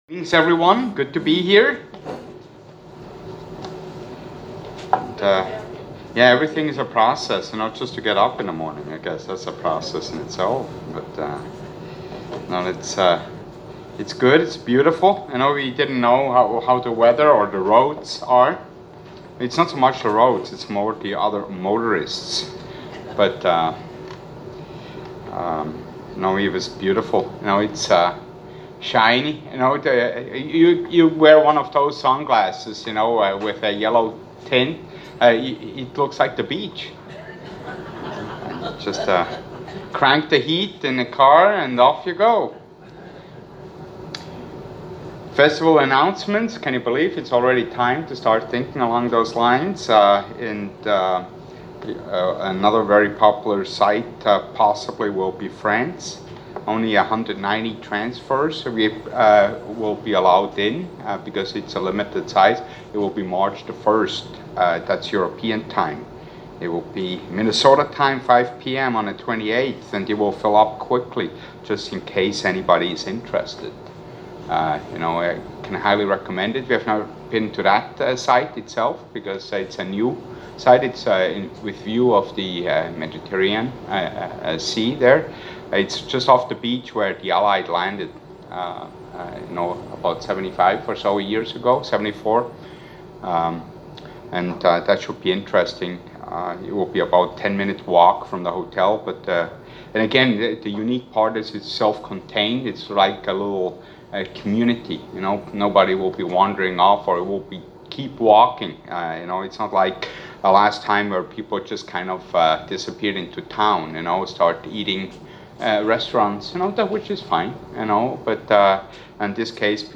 Given in La Crosse, WI